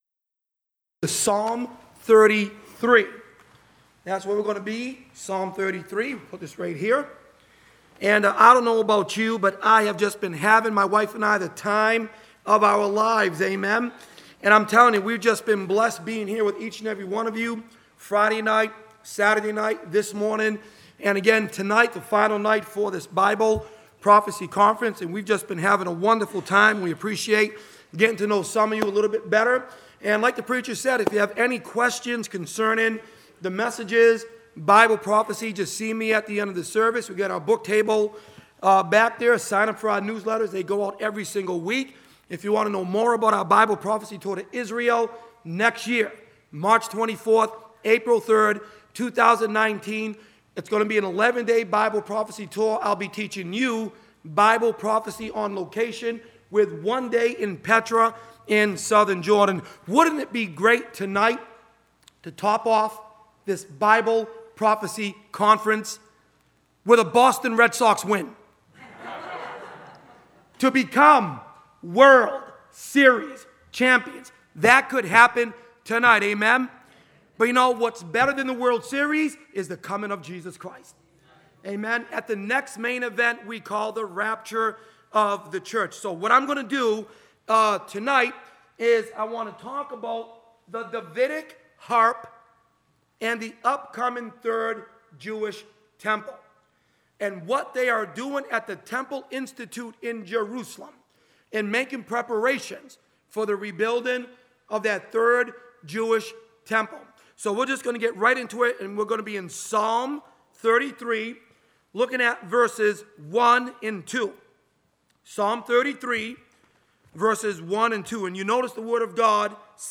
2018 Bible Prophecy Conference (Sunday Night)
2018-Bible-Prophecy-Conf-Sunday-Night.mp3